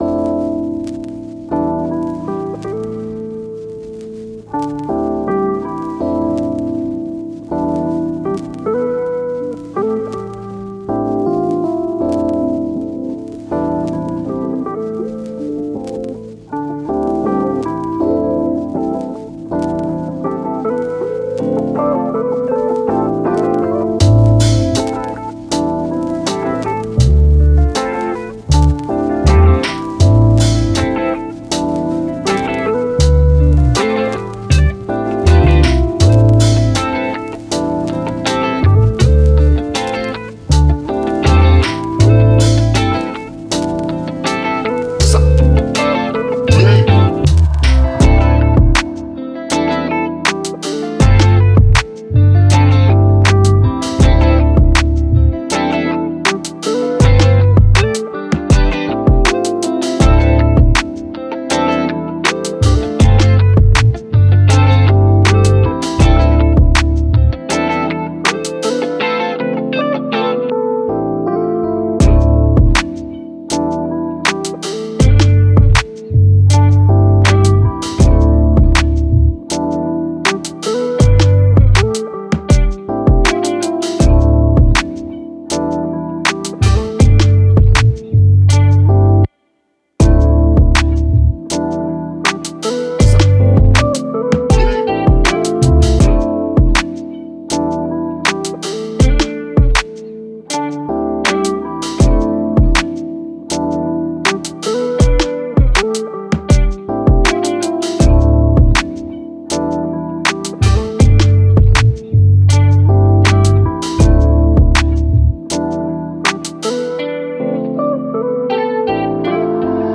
Energetic, Positive, Calm, Vibe
Eletric Guitar, Drum, Piano